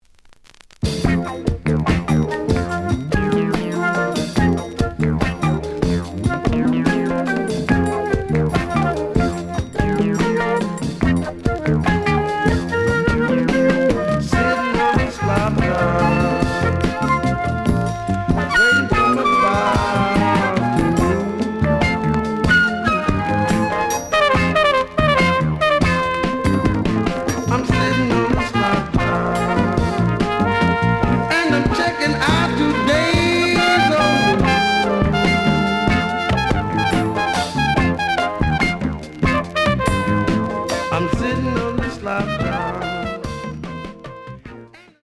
The audio sample is recorded from the actual item.
●Format: 7 inch
●Genre: Jazz Funk / Soul Jazz